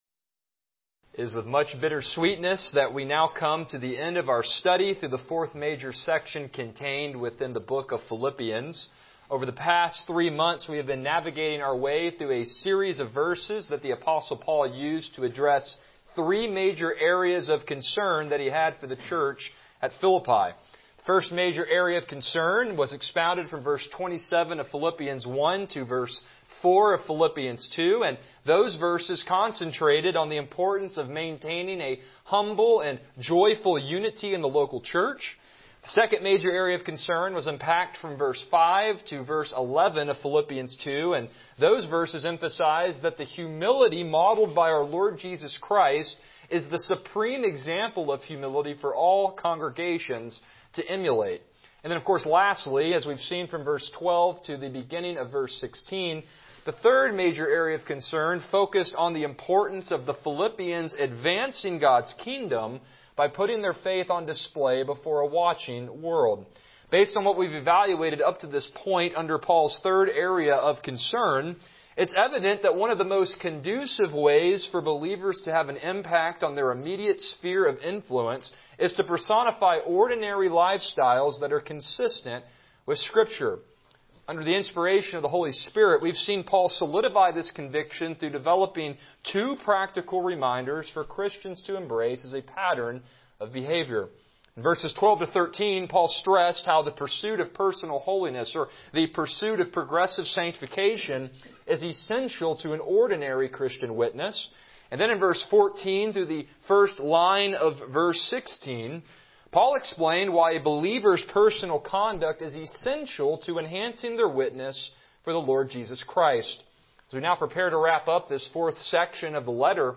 Passage: Philippians 2:16-18 Service Type: Morning Worship